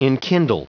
Prononciation du mot enkindle en anglais (fichier audio)
Prononciation du mot : enkindle